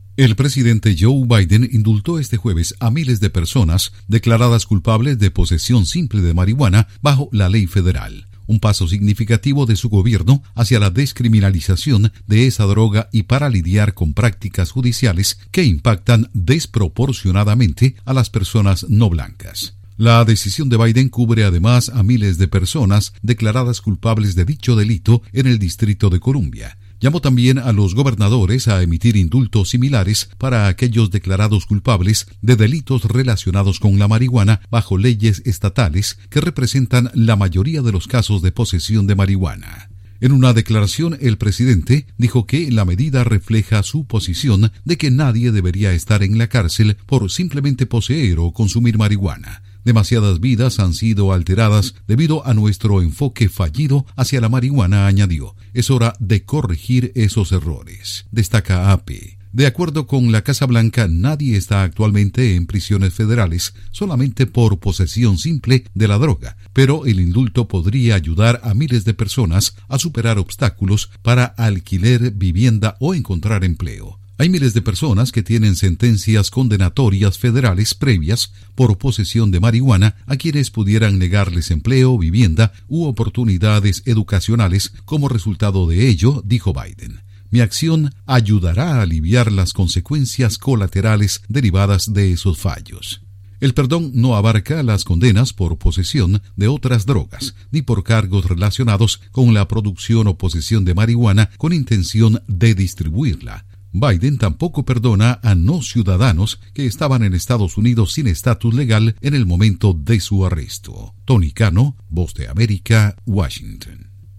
Biden indulta a miles por “posesión simple” de marihuana. Informa desde la Voz de América en Washington